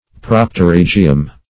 Search Result for " propterygium" : The Collaborative International Dictionary of English v.0.48: Propterygium \Prop`te*ryg"i*um\, n.; pl.